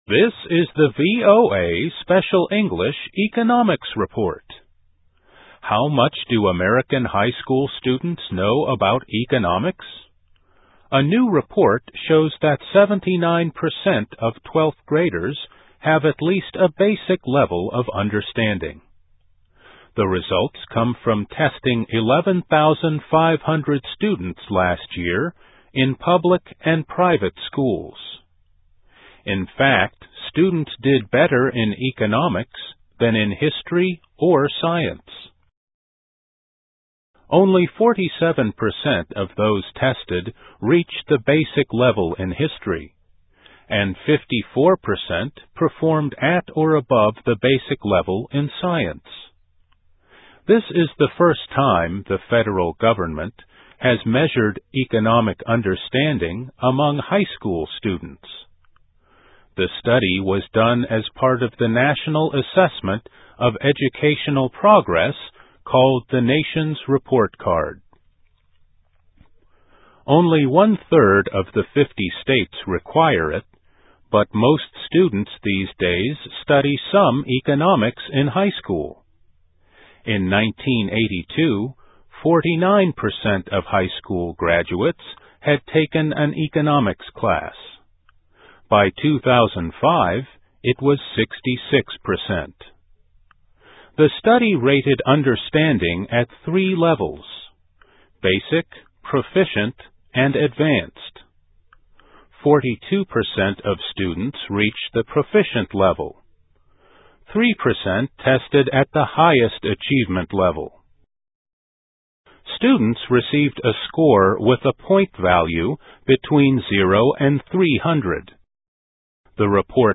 Voice of America Special English